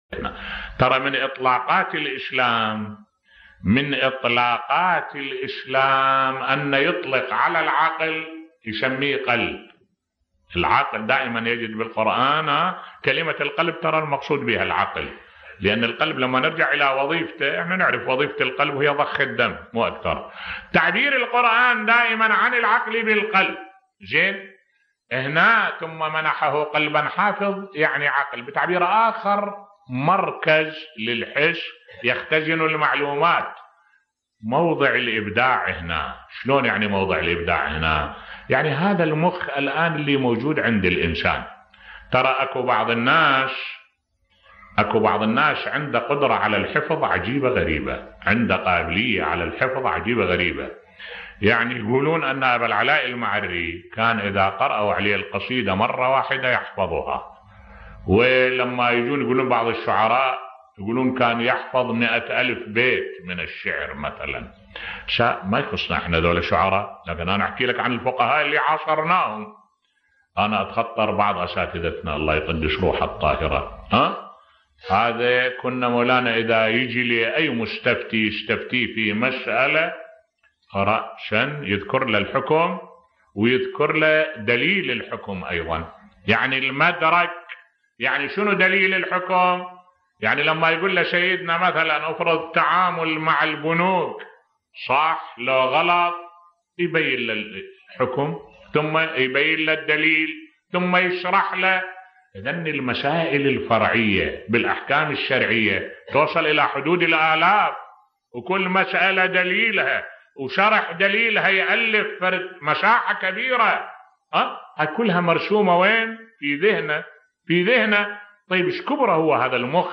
ملف صوتی الابداع في خلق الله للعقل بصوت الشيخ الدكتور أحمد الوائلي